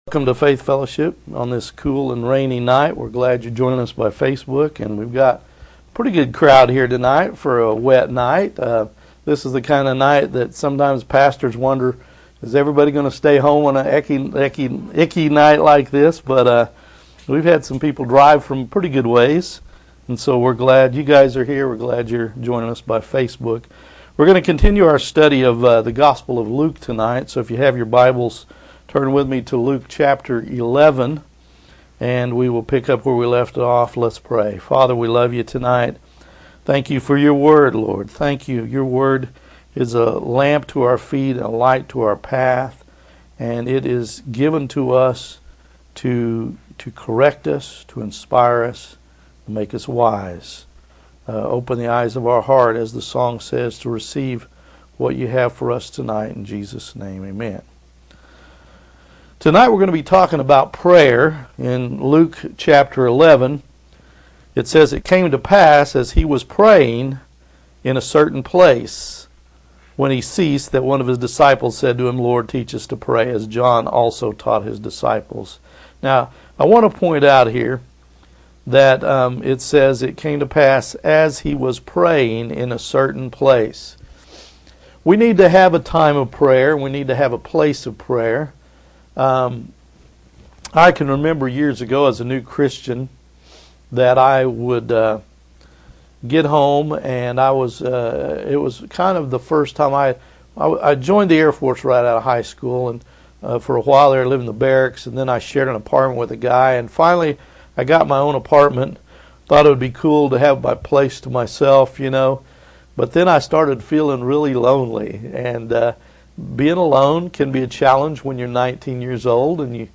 Expositional Sermons